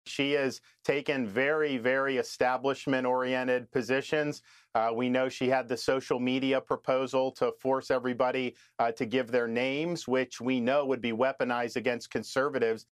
In apparent recognition of the danger that she poses to his candidacy in New Hampshire, DeSantis has trained his political fire on Haley. Speaking with Laura Ingraham Monday night on the Ingraham Angle on the FOX News Channel he said this: